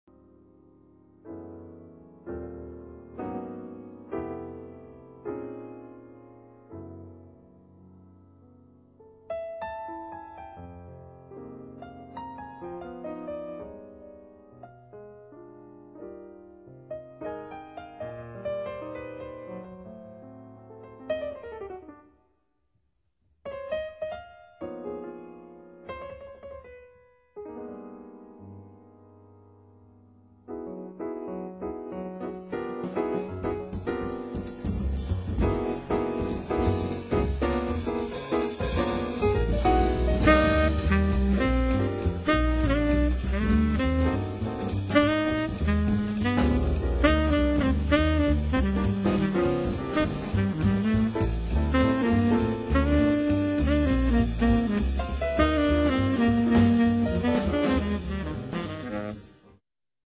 Saxophone
Drums
Piano
Bass